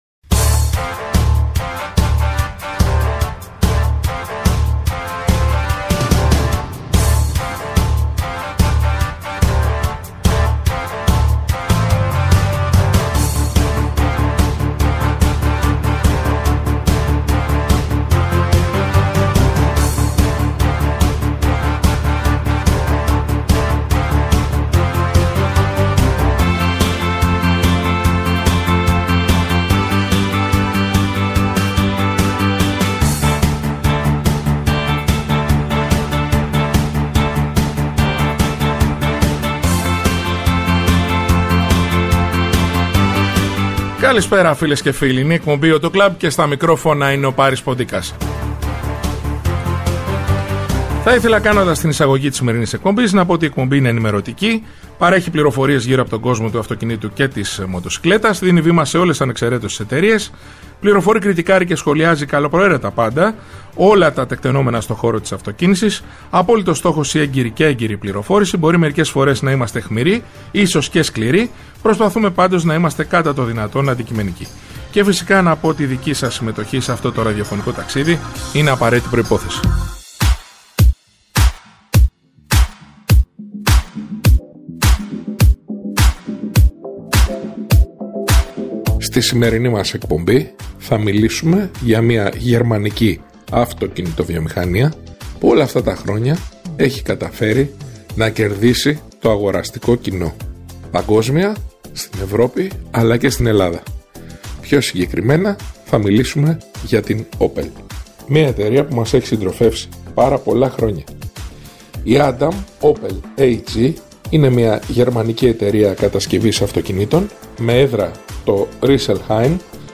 Κριτικάρει και σχολιάζει καλοπροαίρετα πάντα όλα τα τεκτενόμενα στο χώρο της αυτοκίνησης, με απόλυτο στόχο την έγκαιρη και έγκυρη πληροφόρηση για τους ακροατές, με «όπλο» την καλή μουσική και το χιούμορ.